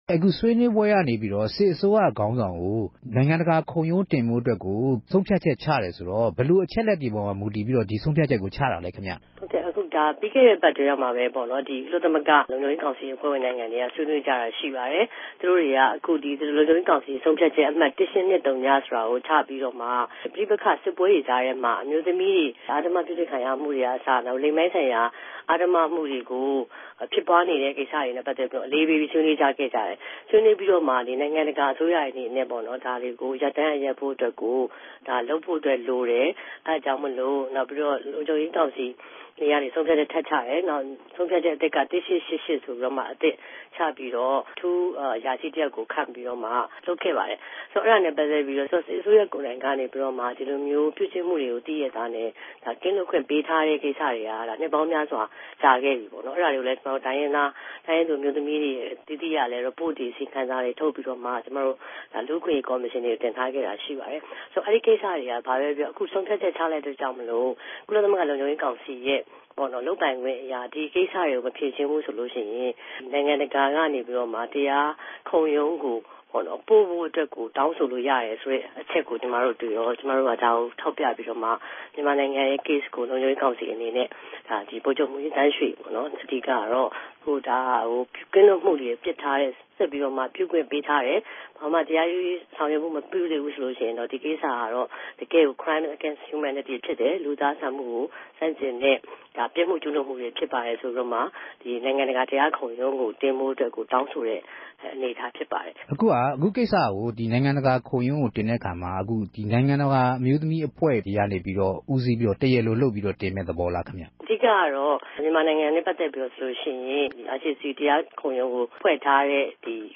ဆက်သြယ်မေးူမန်းခဵက်။